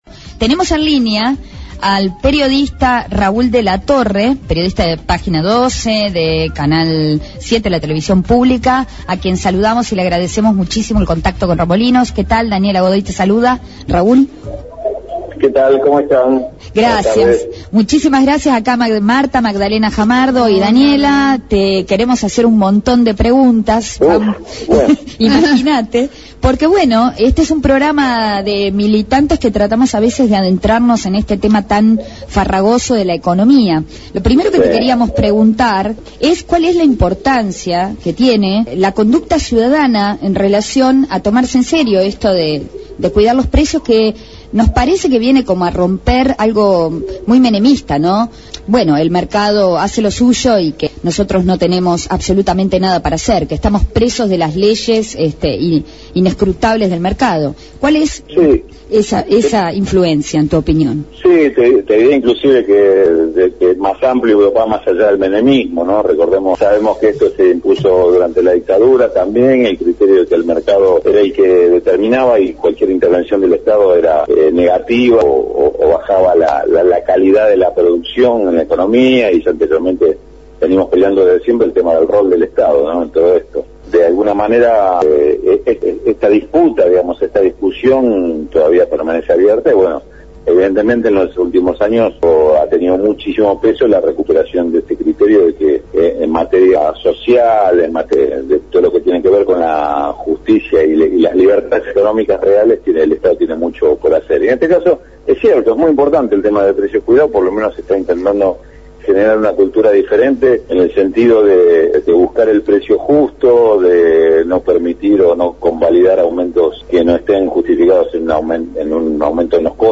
fue entrevistado en Remolinos, Tiempo de Mujeres.